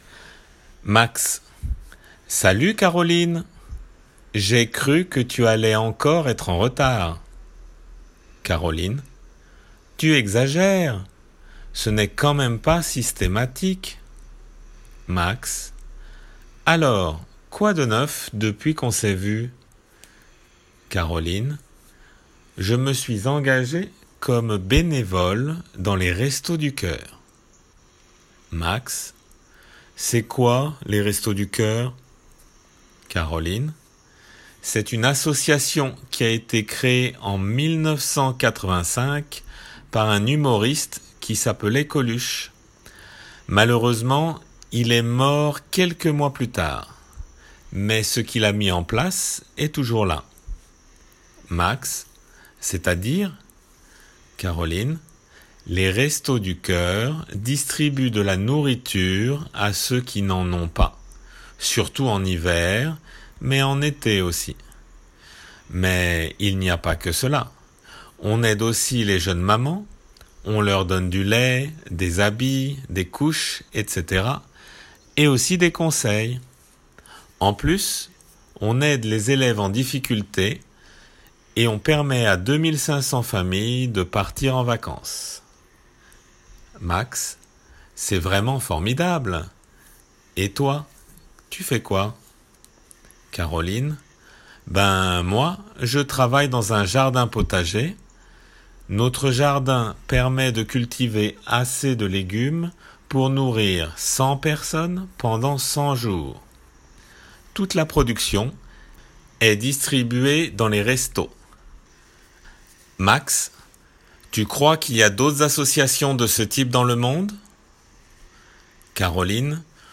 長い会話なので　 前半部　と　後半部　に分けて練習用に掲載しています。